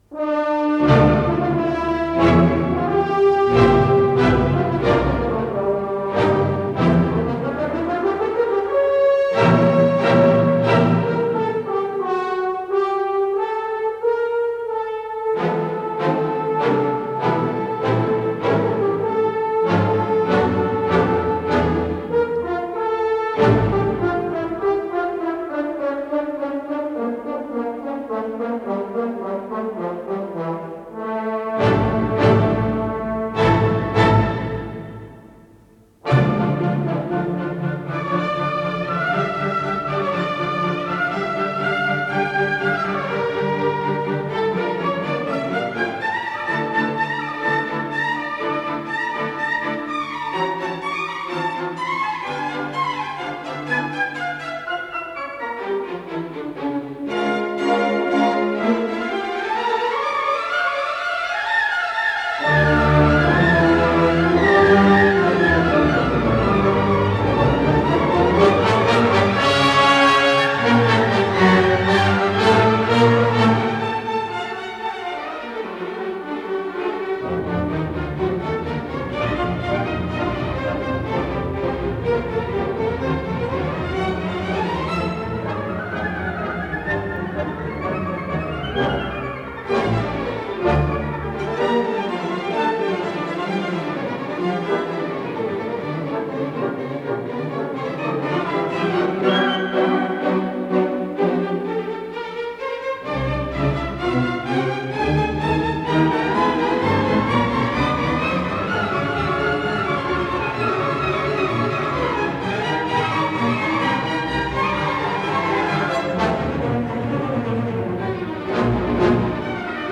Ре минор